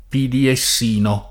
piddiessino [piddieSS&no] (meglio che pidiessino [id. o